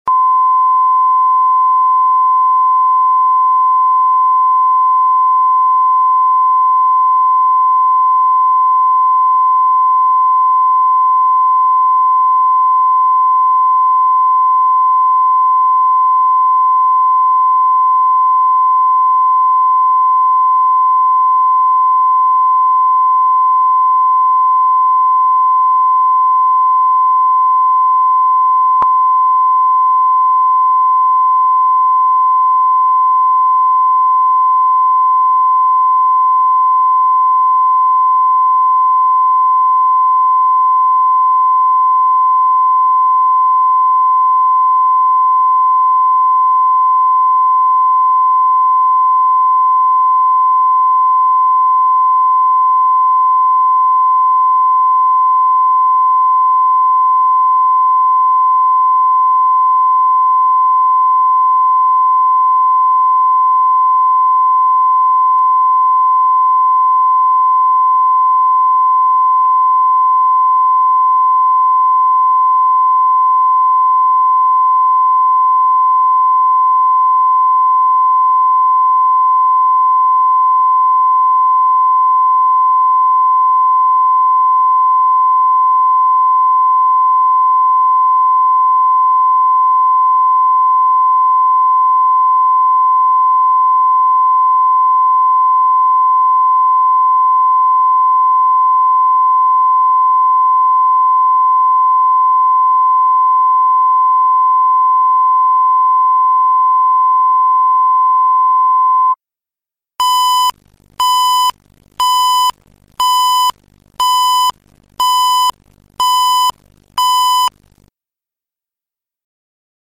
Аудиокнига Любимый с прицепом | Библиотека аудиокниг